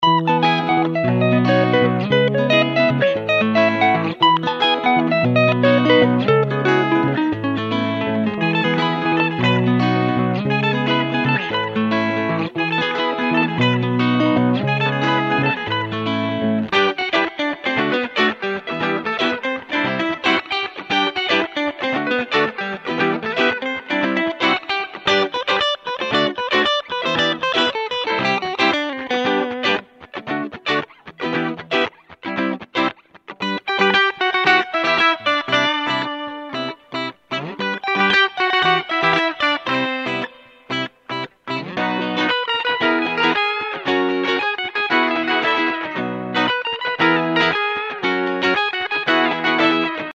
Immerse yourself in the infectious rhythms and vibrant melodies crafted by seasoned African guitarists. From the mesmerizing sounds of highlife and Afrobeat to the intricate patterns of soukous and Afro-jazz, each loop captures the essence of Africa’s diverse musical landscape. Alongside expressive guitar riffs, you’ll find 13 ensemble loops that create lush, full-bodied harmonies and 43 rhythm loops designed to add groove and movement to your tracks.
Discover a treasure trove of authentic guitar performances, meticulously recorded to capture the warmth and character of traditional African instruments.
African-Guitar-Riffs-Vol-2.mp3